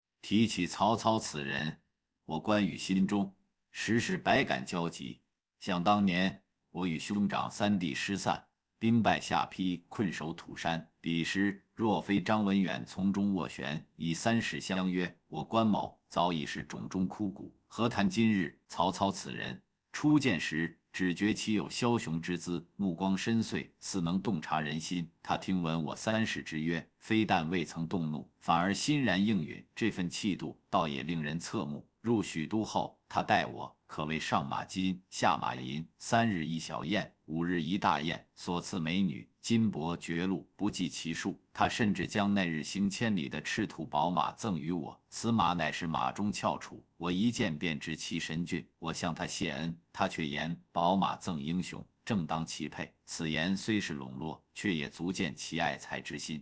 深沉悬疑